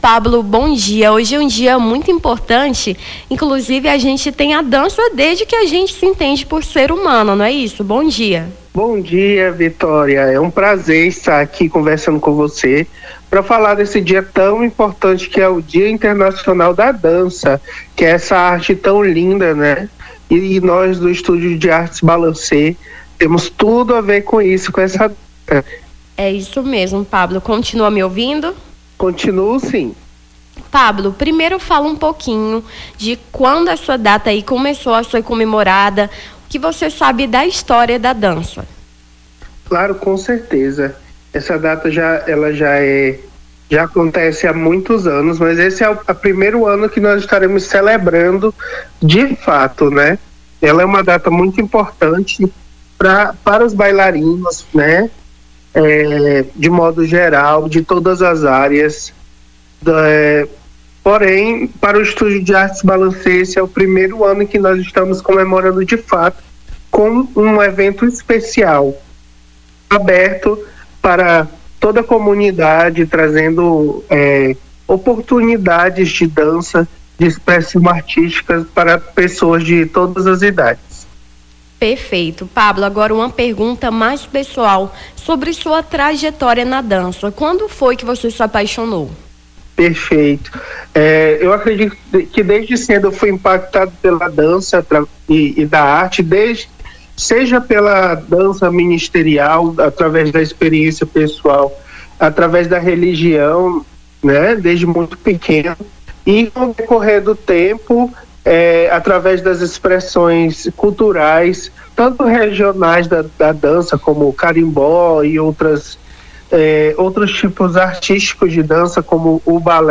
Nome do Artista - CENSURA - ENTREVISTA (DIA INTERNACIONAL DA DANCA) 29-04-25.mp3